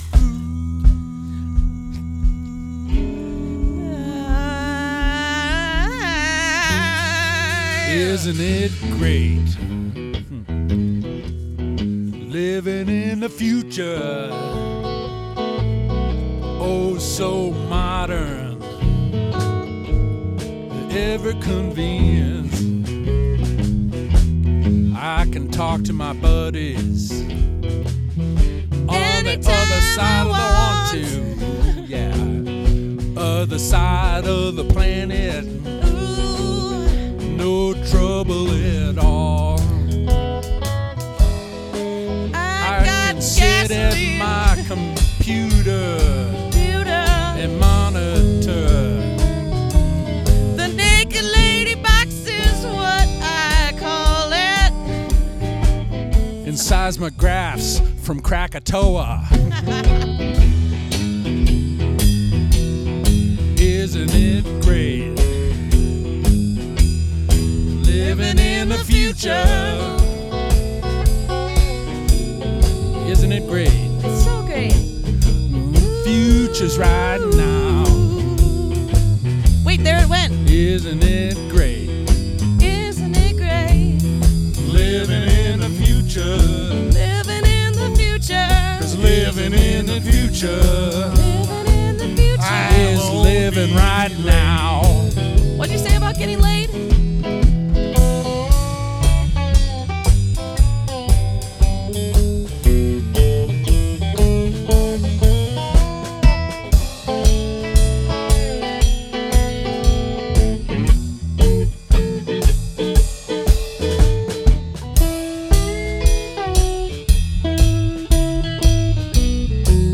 drums
bass
vocals